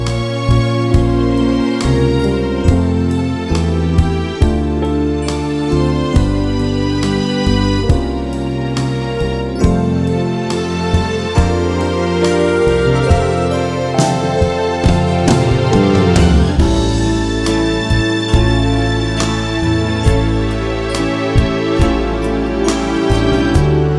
No Backing Vocals Crooners 3:59 Buy £1.50